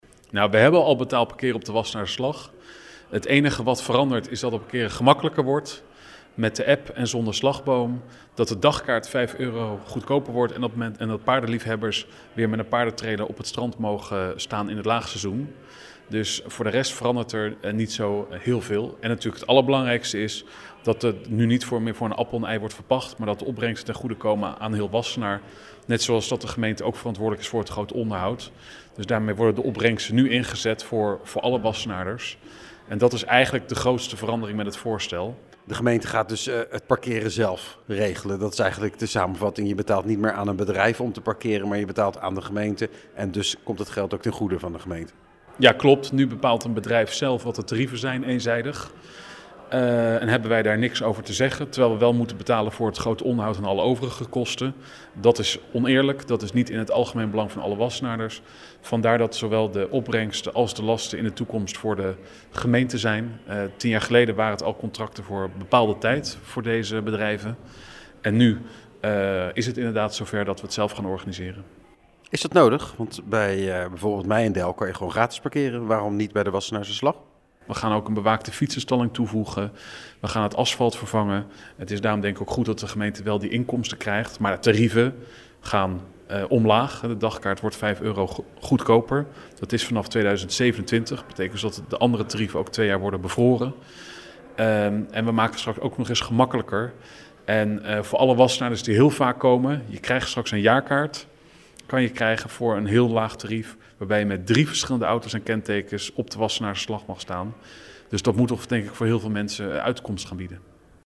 Wethouder Laurens van Doeveren over betaald parkeren aan de Wassenaarse Slag: